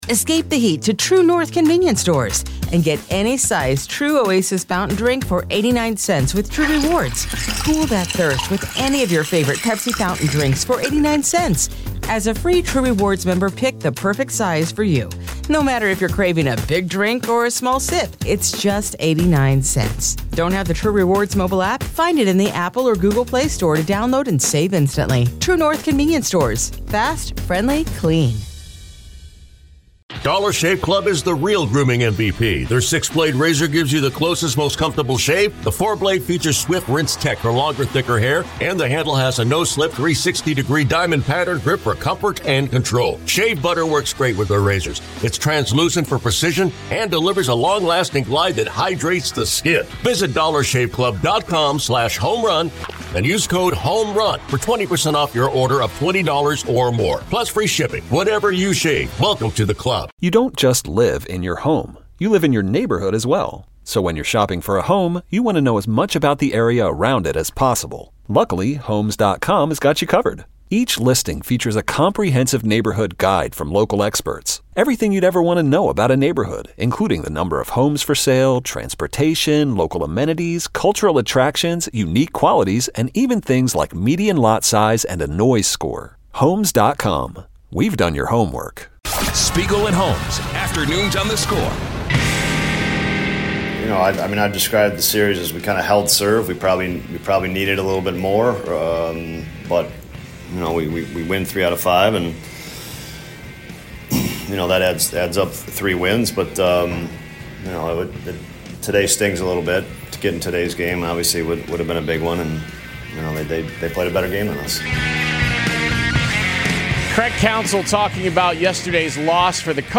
Callers critical of Craig Counsell's handling of Tucker's injury and his lineup decisions (Hour 2)